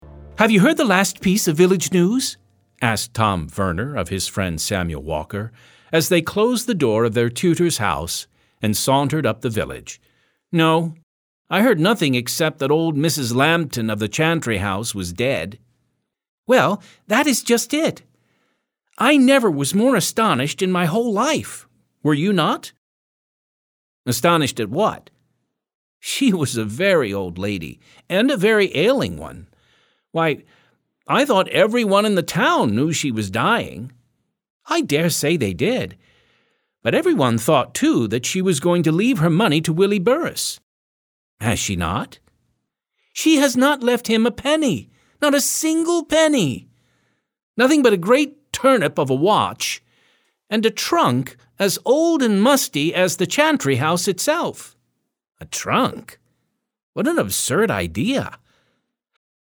Audiobook: Willy's Trunk - MP3 download - Lamplighter Ministries